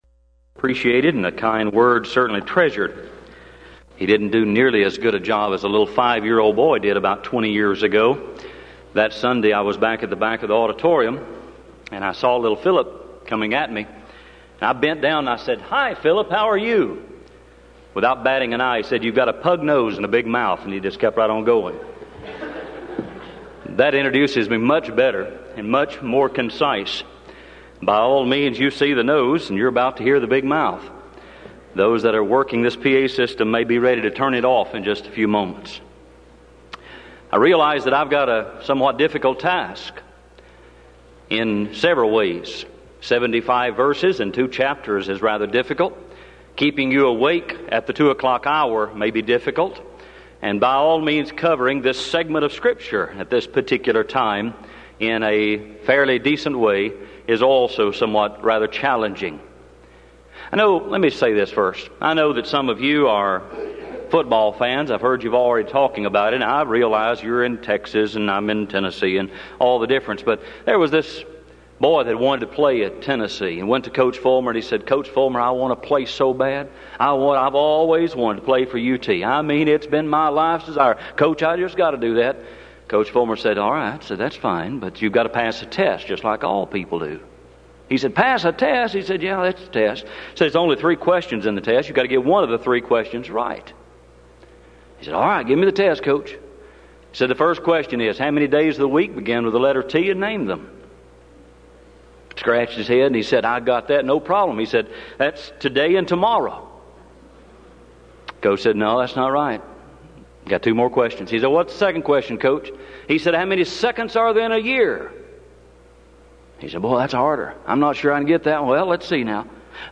Event: 1994 Denton Lectures Theme/Title: Studies In Joshua, Judges And Ruth
lecture